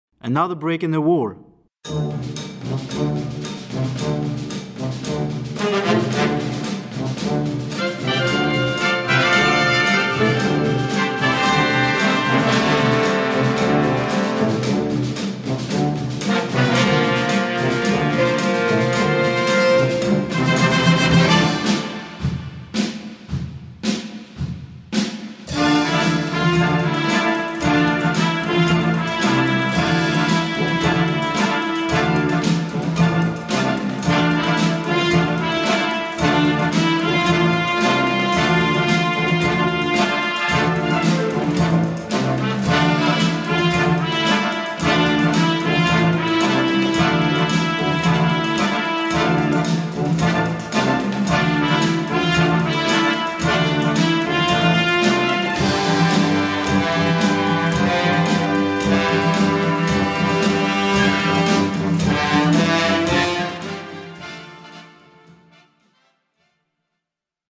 Recueil pour Harmonie/fanfare